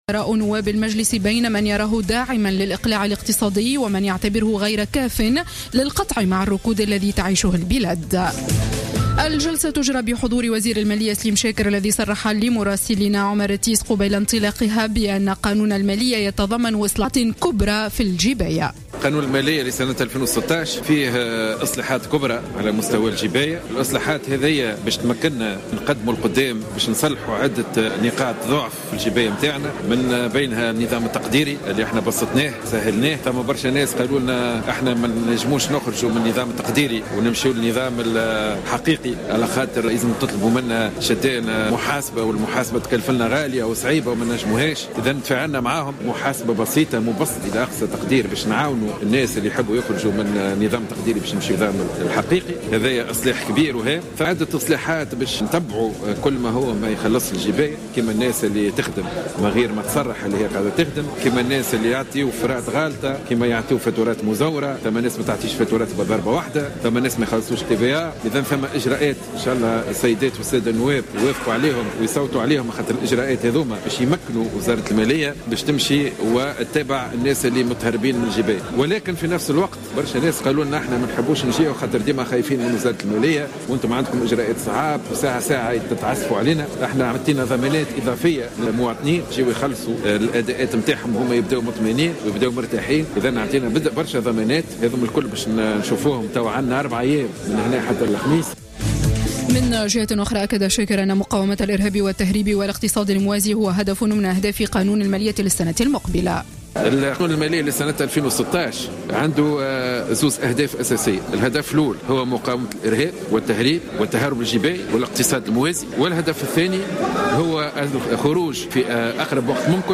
نشرة أخبار السابعة مساء ليوم الاثنين 7 ديسمبر 2015